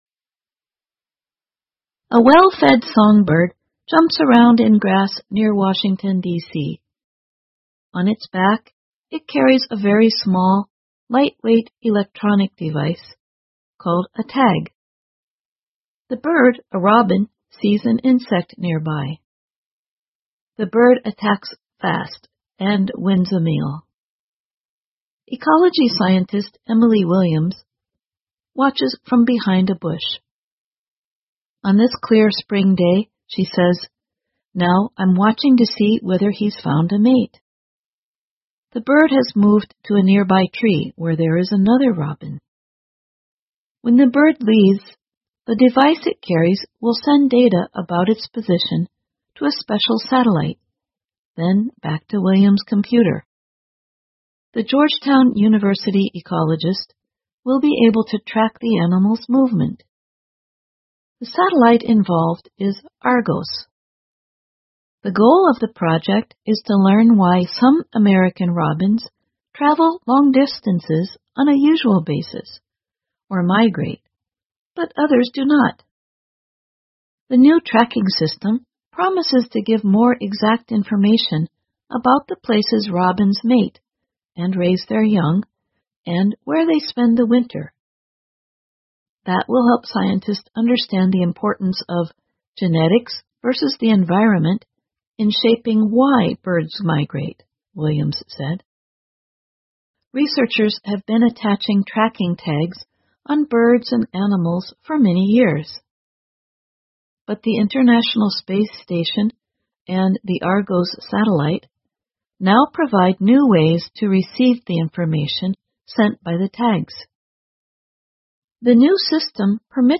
VOA慢速英语--科技为科学家提供了更好的跟踪鸟类的方法 听力文件下载—在线英语听力室